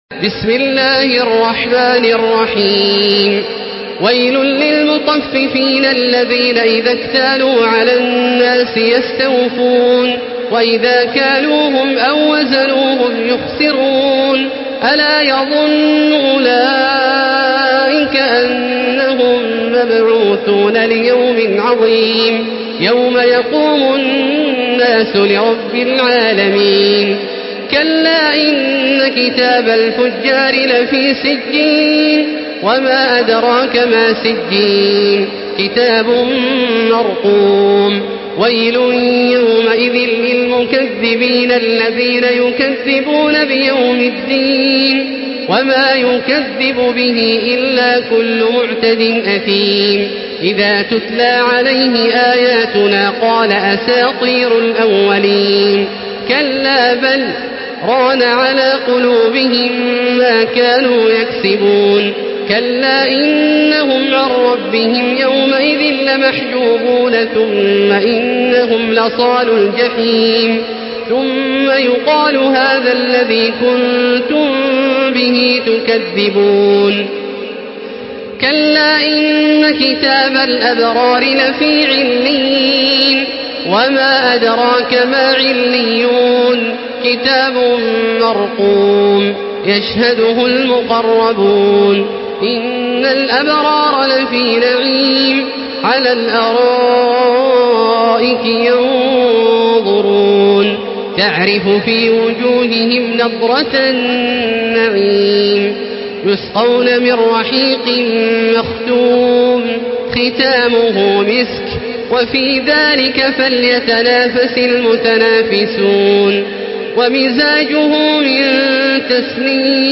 Surah Müteffifin MP3 in the Voice of Makkah Taraweeh 1435 in Hafs Narration
Murattal